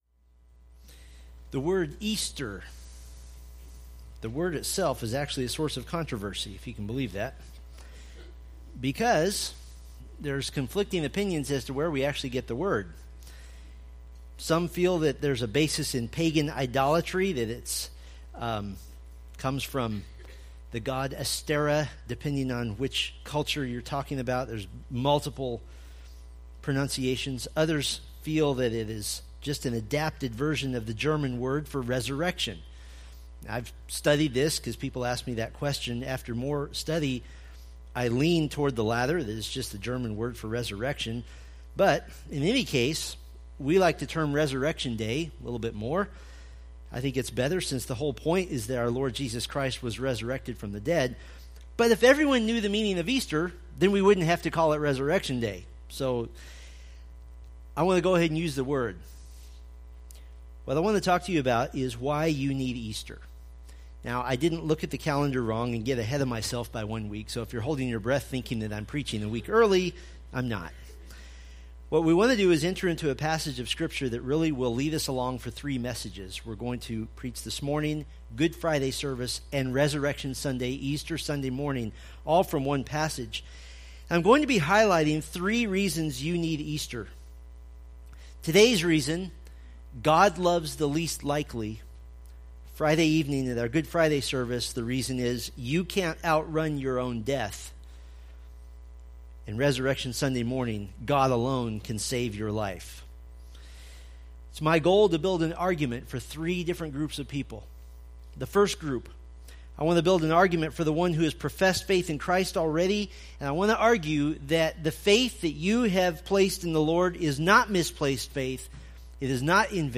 1 Kings Sermon Series